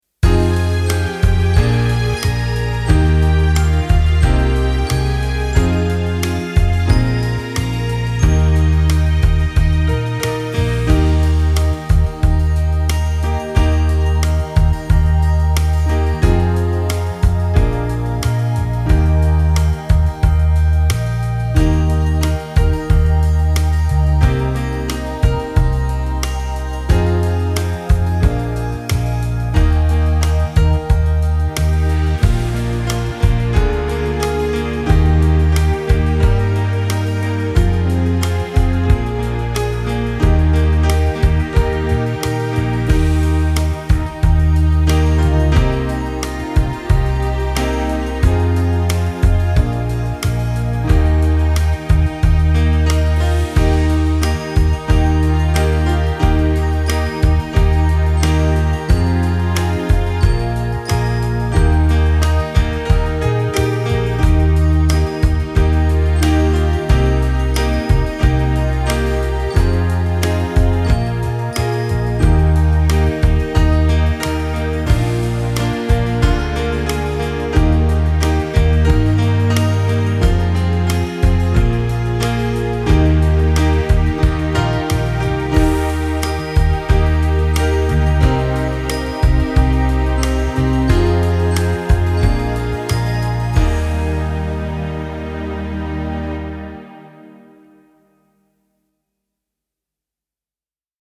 Besetzung: Instrumentalnoten für Posaune